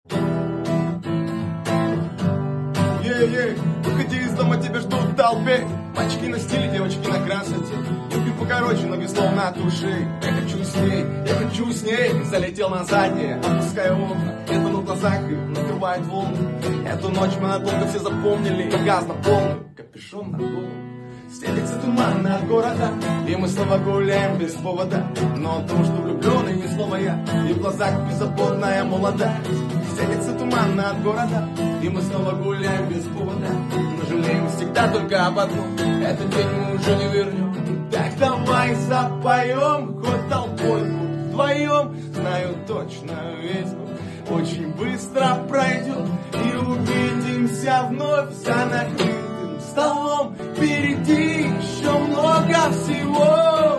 • Качество: 128 kbps, Stereo